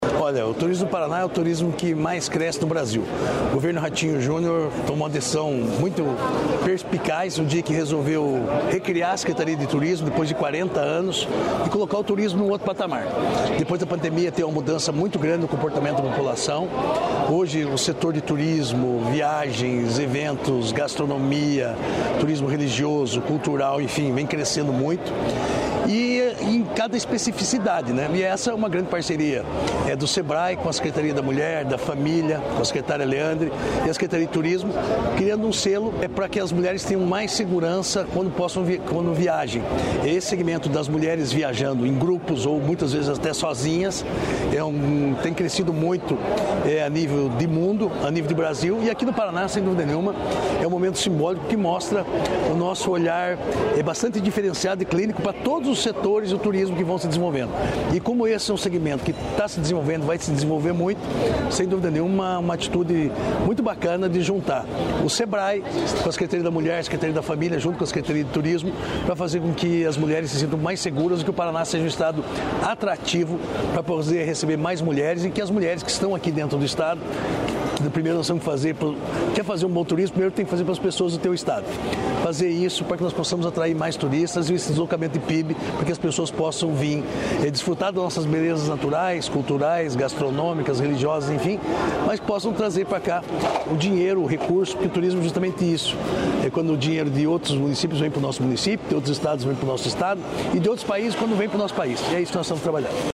Sonora do secretário do Turismo, Márcio nunes, sobre segurança das mulheres no turismo | Governo do Estado do Paraná